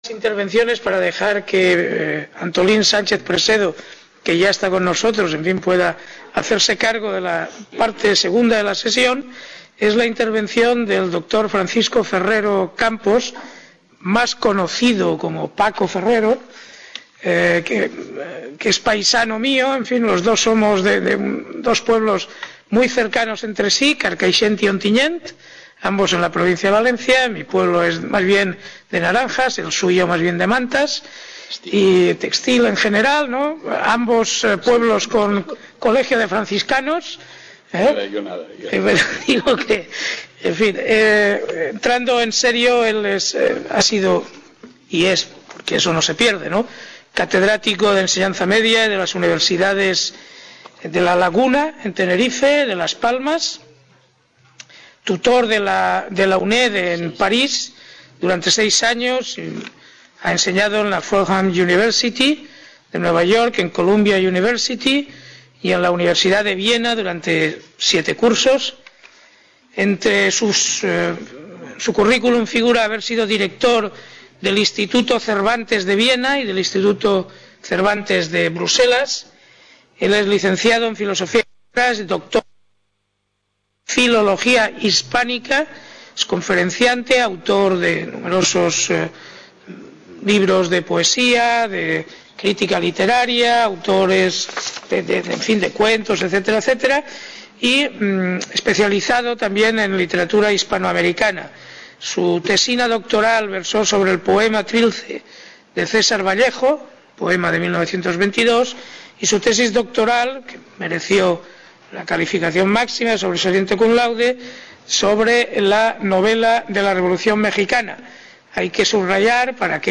MESA REDONDA
Reunion, debate, coloquio...